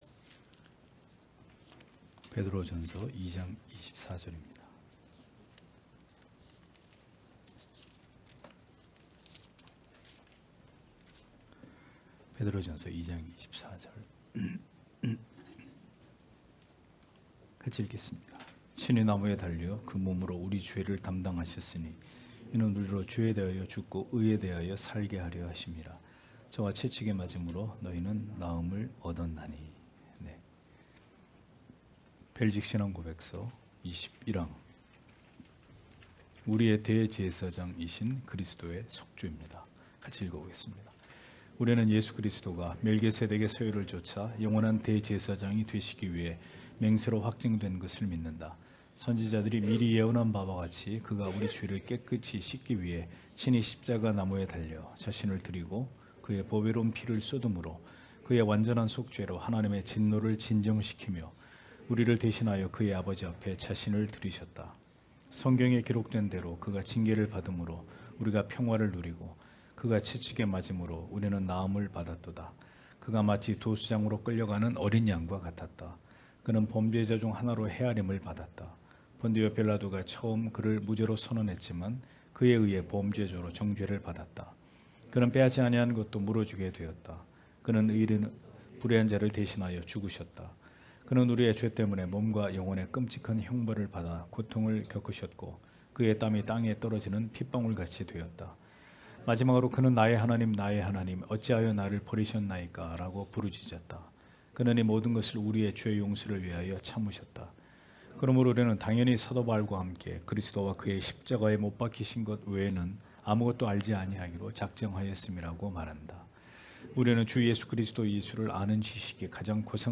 주일오후예배 - [벨직 신앙고백서 해설 22] 제21항 우리의 대제사장이신 그리스도의 속죄 (벧전 2장 24절)